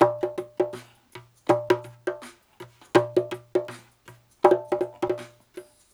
81-BONGO1.wav